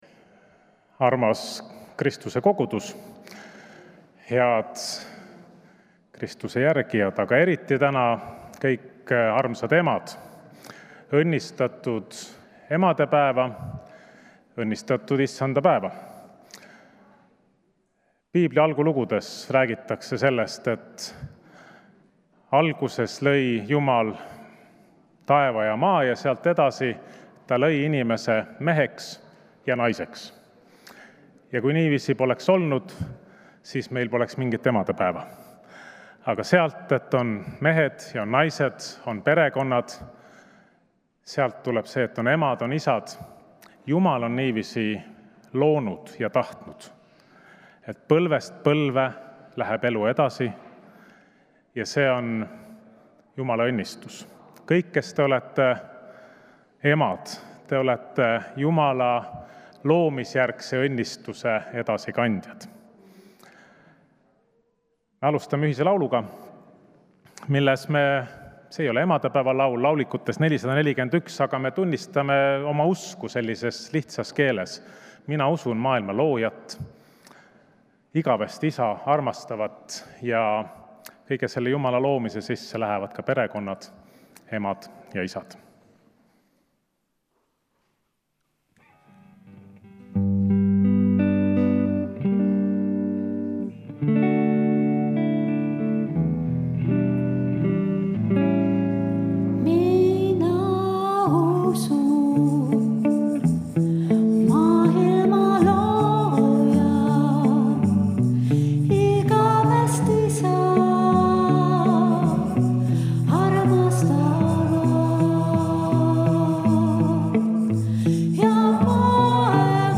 Jutlus